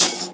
Track 02 - Percussion OS 06.wav